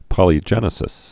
(pŏlē-jĕnĭ-sĭs)